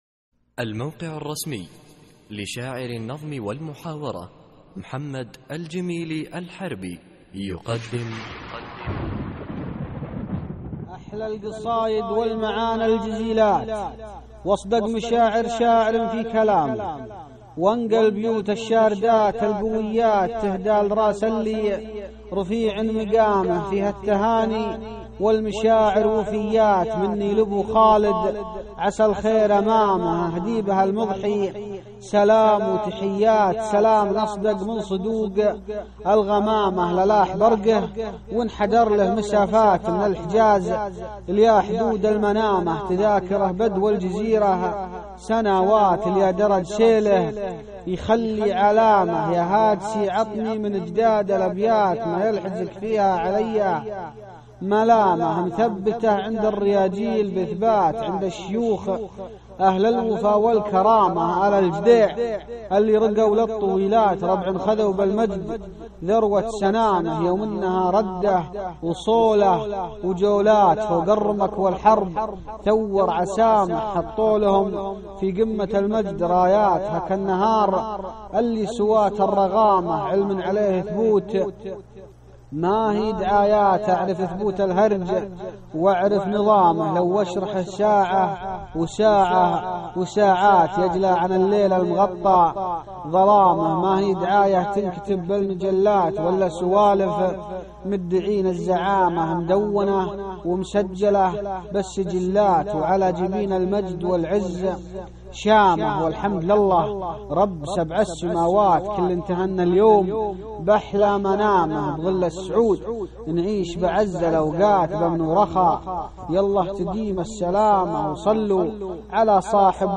القصـائــد الصوتية